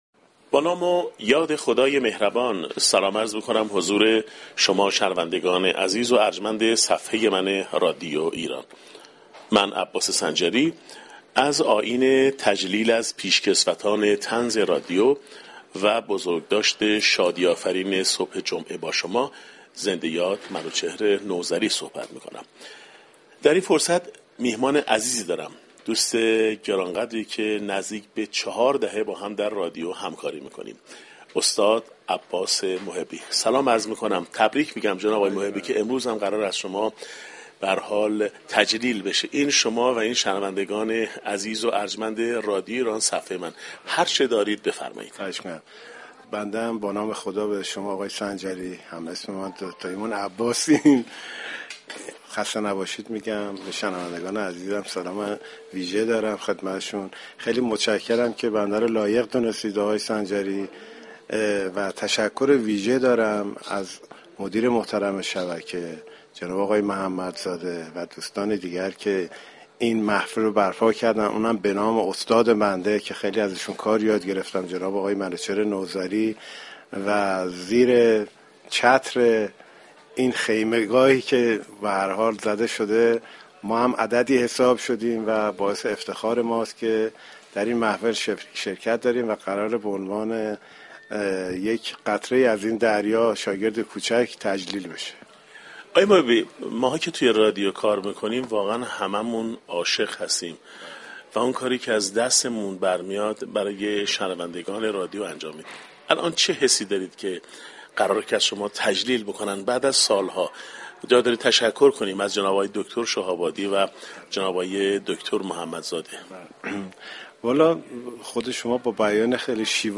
در حاشیه آیین تجلیل از پیشكسوتان طنز رادیو